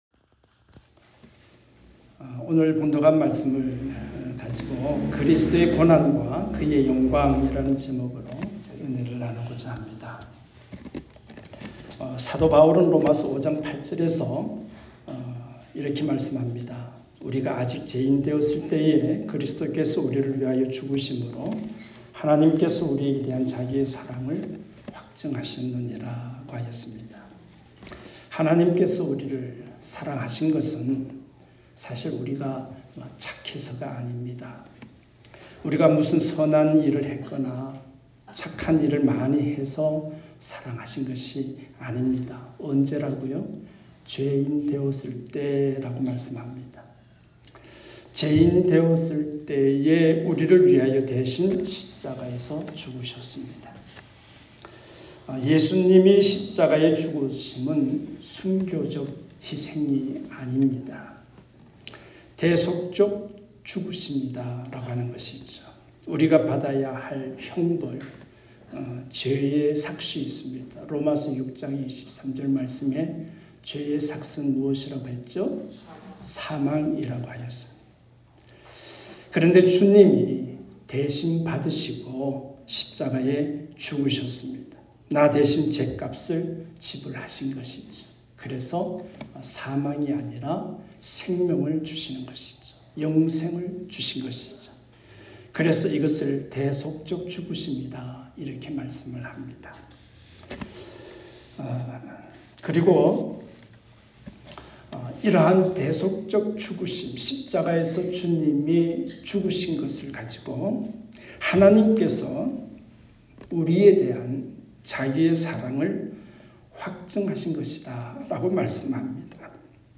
주일음성설교 에 포함되어 있습니다.